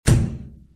stamp.mp3